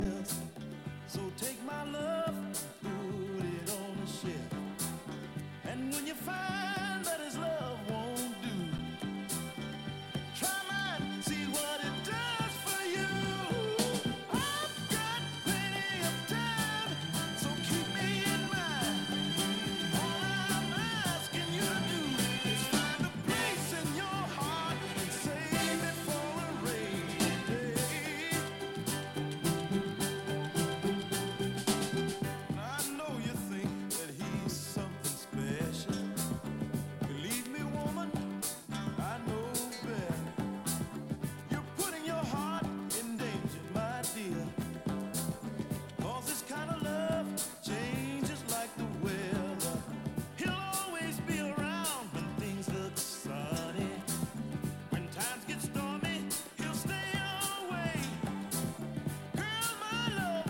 psychedelic soul